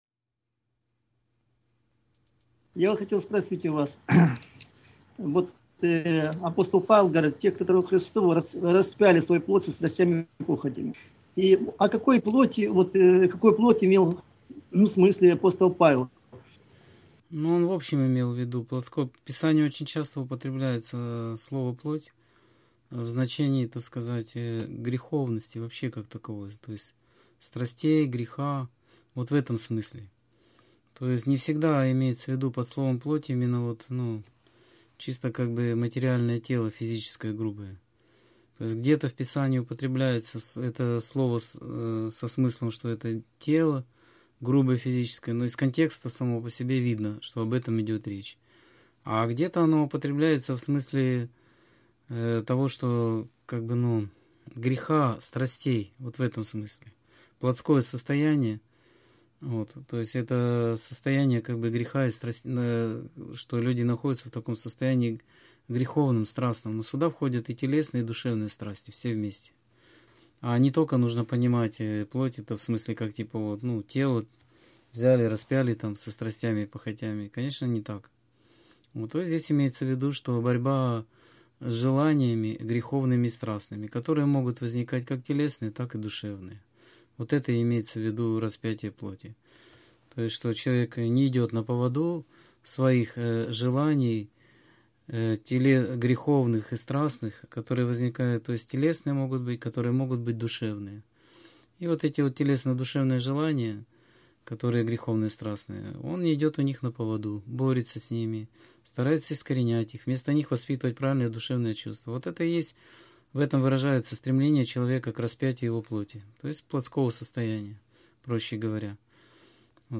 Скайп-беседа 17.10.2015